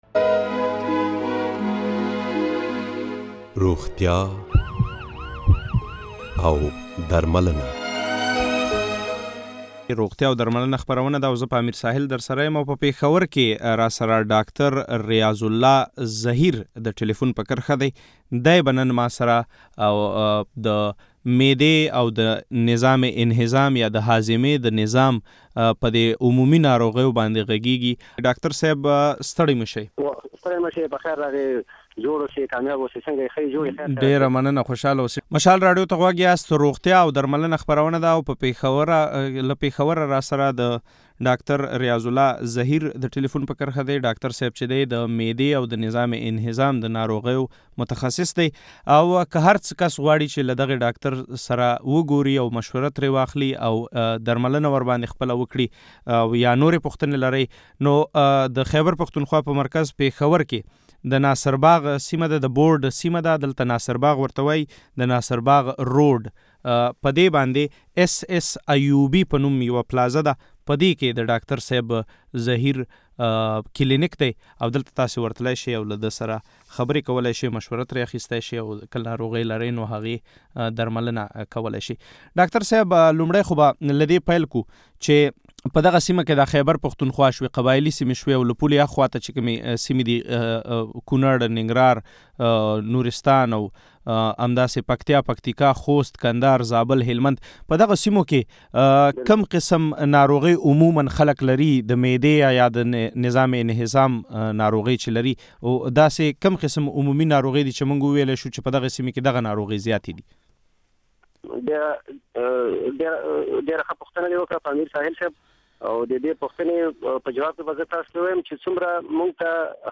د مشال تر رڼا لاندي بحث هم دې موضوع ته ځانګړی شوی دی.
د بحث لپاره د بلوچستان د کورنيو چارو له سيکټر نصيب الله بازی سره هم ځانګړې مرکه شوې ده.